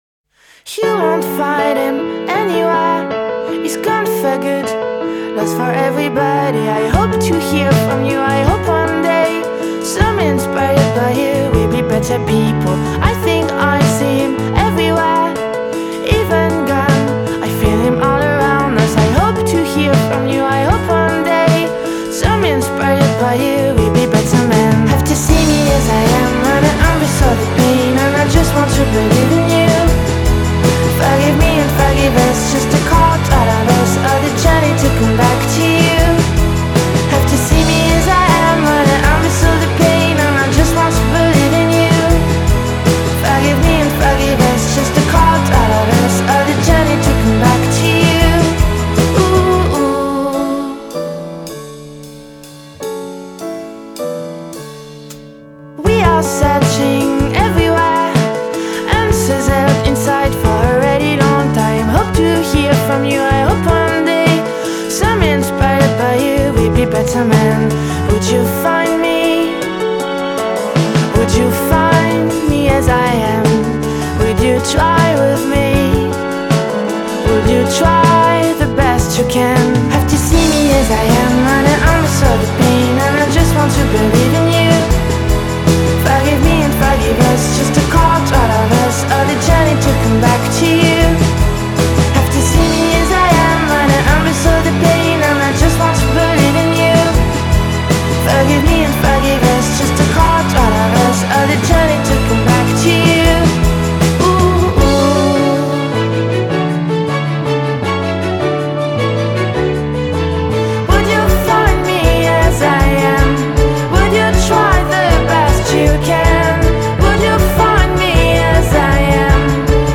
Genre: Indiepop/Piano Rock/Female Vocal